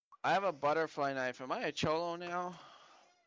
Butterfly Knife